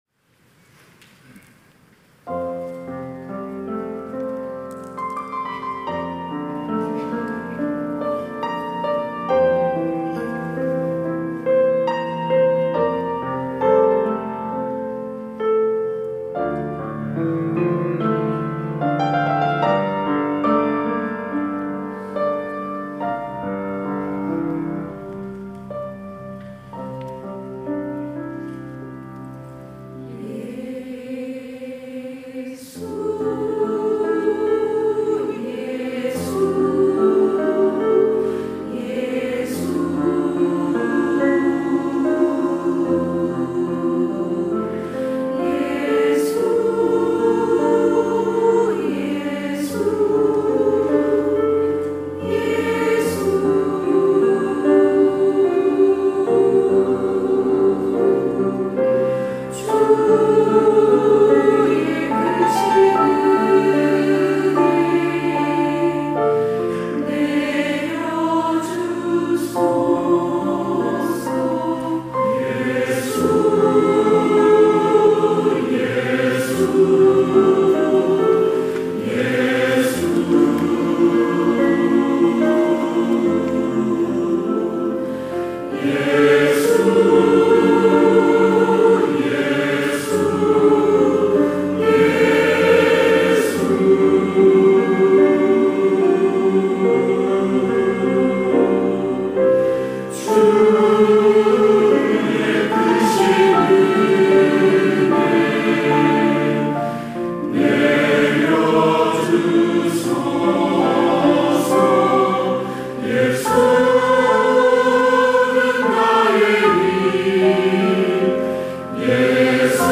찬양대 시온